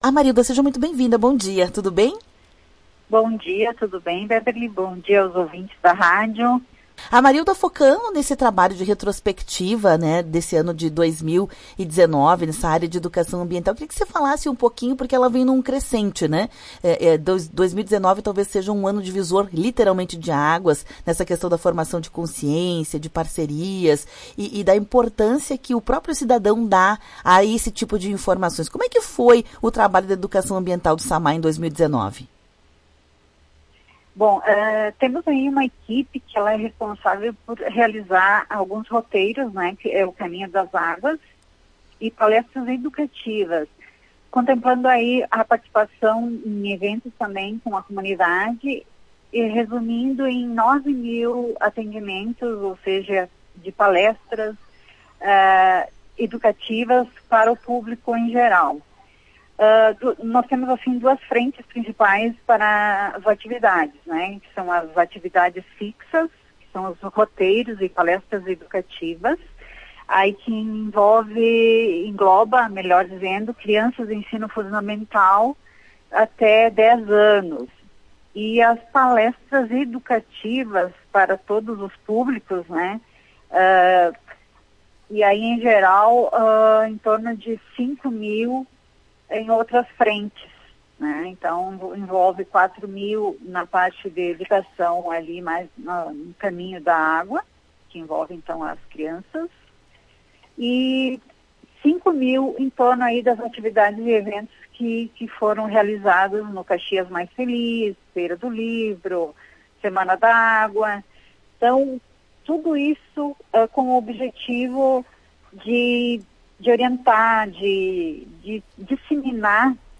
A diretora-presidente da Autarquia, Amarilda Bortolotto, falou sobre o assunto em entrevista ao programa Temática na manhã desta terça-feira.